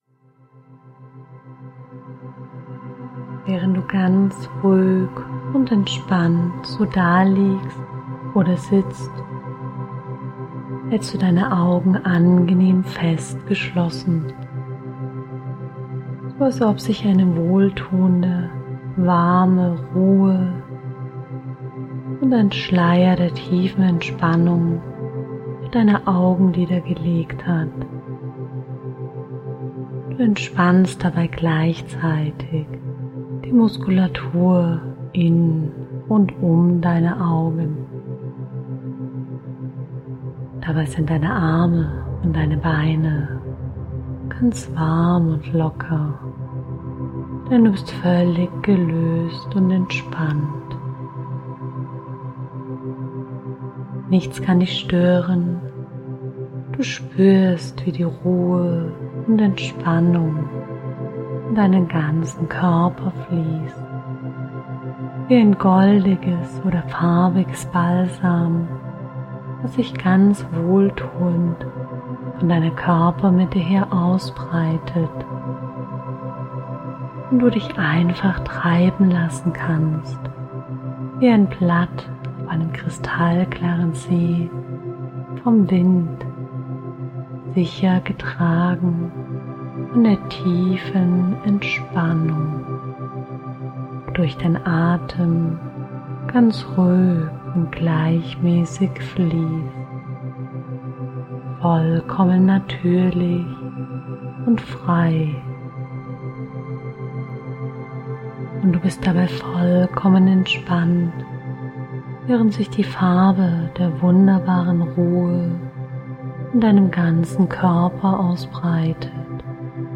Diese Hörbuch-Anwendung ist bestens geeignet Körper und Geist zu entspannen und somit schnell und zuverlässig bei Einschlafstörungen zu helfen. Die Hypnose führt Sie in einen angenehmen Entspannungszustand und Sie nähern sich der Schwelle zum Schlaf.
besserschlafenmithypnosehoerprobe.mp3